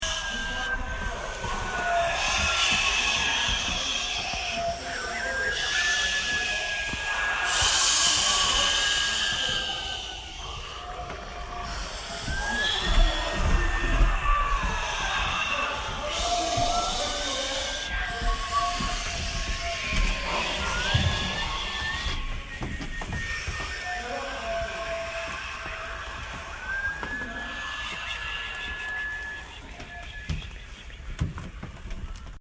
Bill of Rights to the stairs and then the street 11:53 PM 4/12/18
Field Recording
blowing , creak , door , street , wind
Wind.mp3